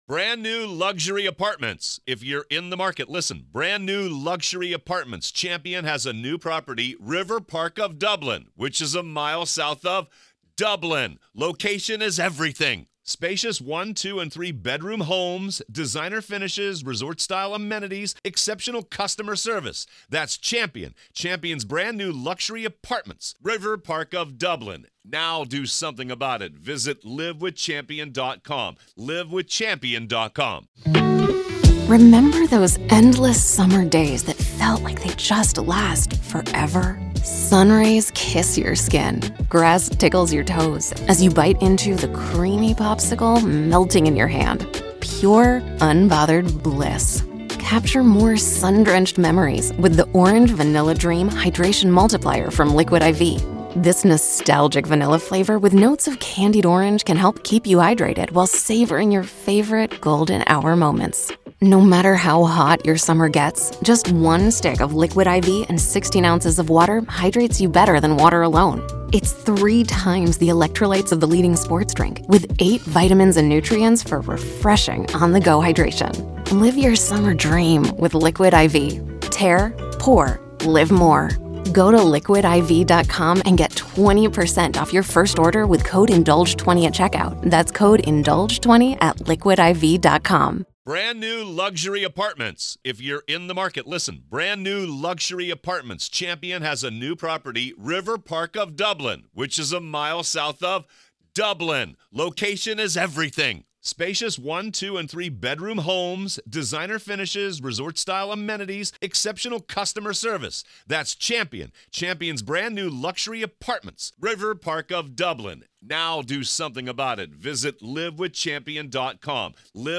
During their conversation